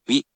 We’re going to show you the character, then you you can click the play button to hear QUIZBO™ sound it out for you.
In romaji, 「ゐ」 is transliterated as 「wi」or 「i」which sounds sort of like 「whee」, but can be written as 「うぃ